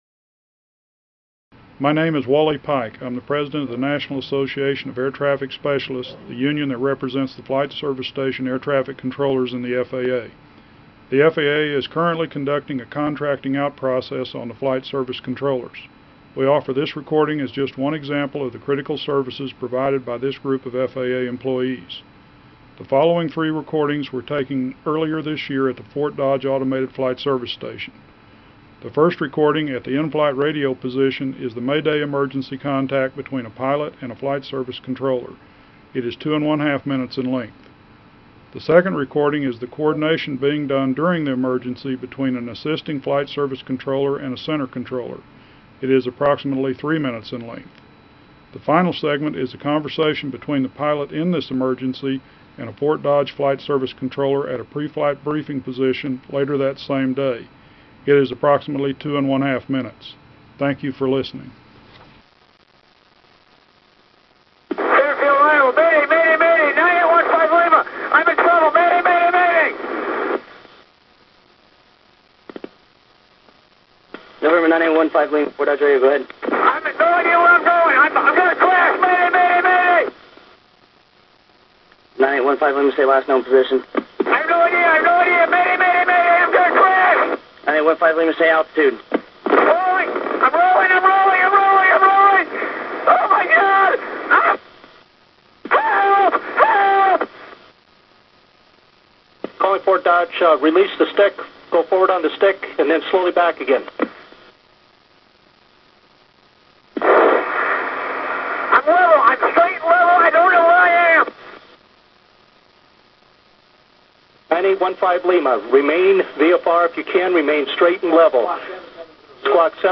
Audio clip between an FSS air traffic specialist and pilot.